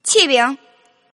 Index of /client/common_mahjong_tianjin/mahjongjinghai/update/1124/res/sfx/tianjin/woman/